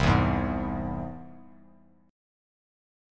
C+ Chord
Listen to C+ strummed